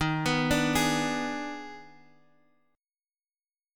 EbM7sus4#5 Chord